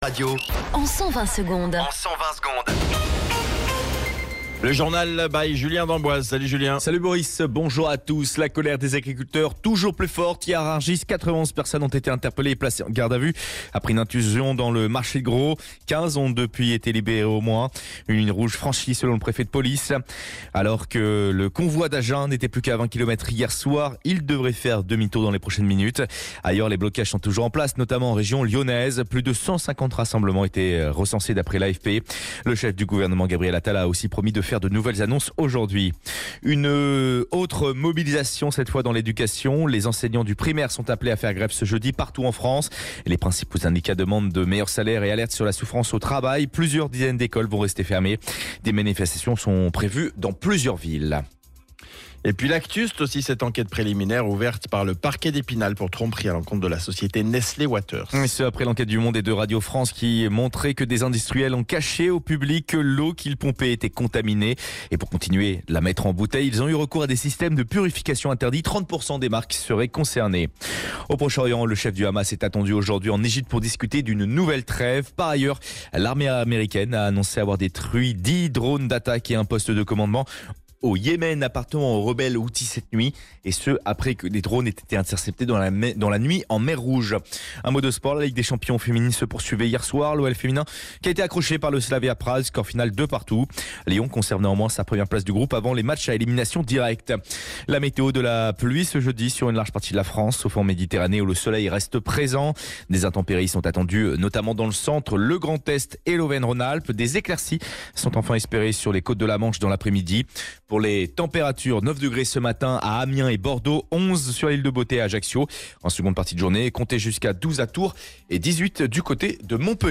Flash Info National 01 Février 2024 Du 01/02/2024 à 07h10 Flash Info Télécharger le podcast Partager : À découvrir Alerte Canicule : Le Eddie’s Dive Bar d’Iron Maiden débarque en France !